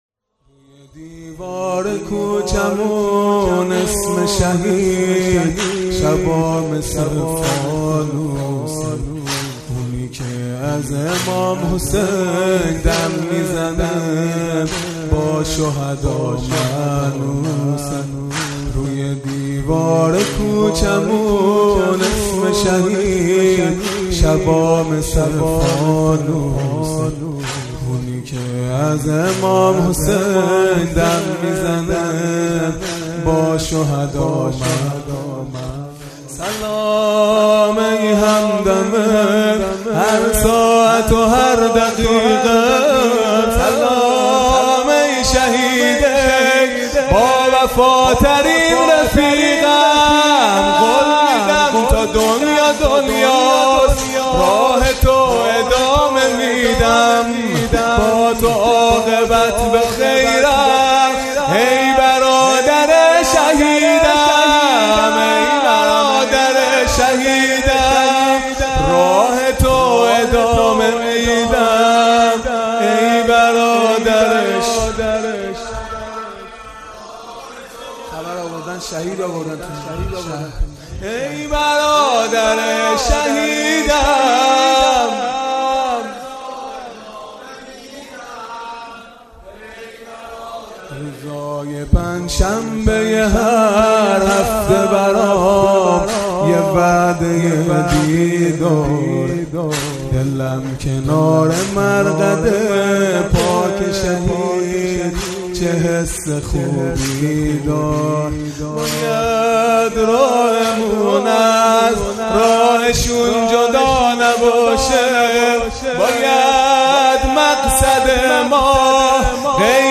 خیمه گاه - هیئت بچه های فاطمه (س) - شور پایانی | دیوار کوچمون اسم شهید، شبا مثل فانوسه | شنبه ۲۷ دی ۹۹
فاطمیه (شب سوم)